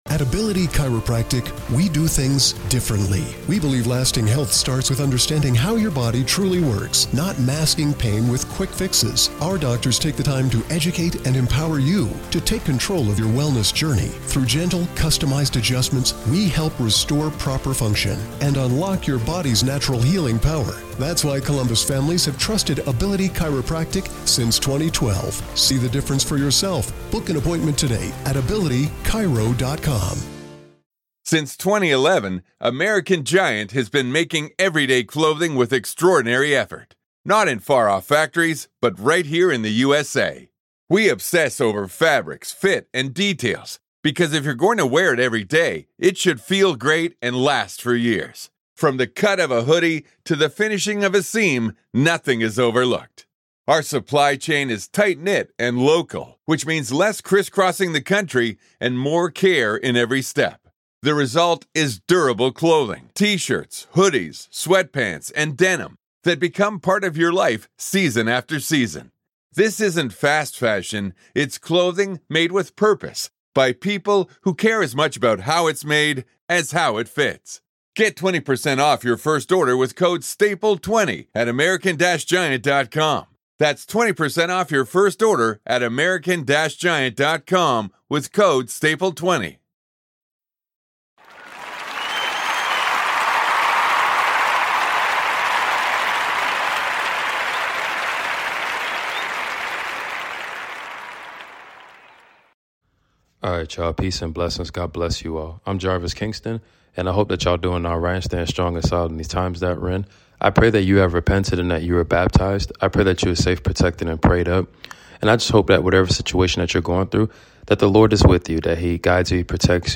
Lost books of the Bible reading ! David defeats Goliath !